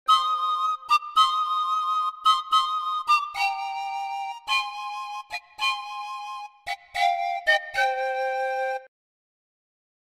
andean-stroll-panpipe-02.mp3